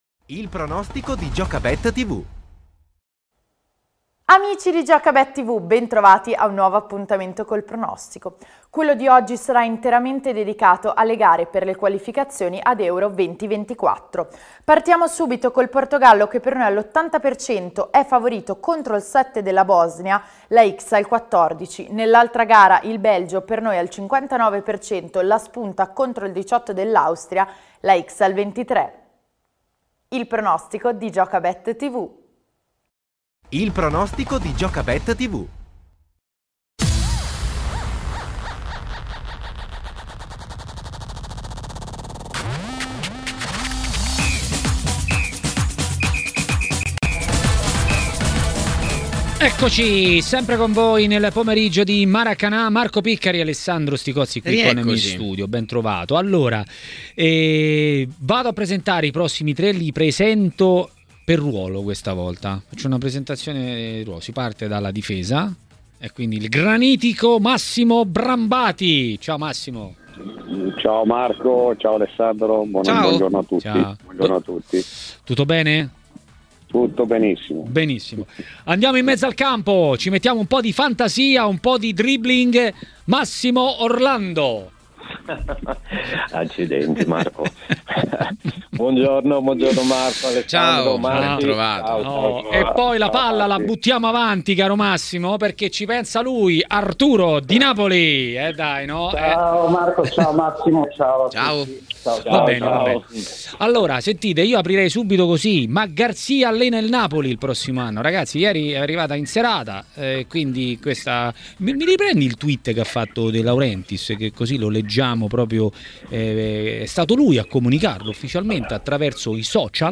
A commentare le notizie del giorno a Maracanà, nel pomeriggio di TMW Radio, è stato l'ex calciatore Massimo Orlando.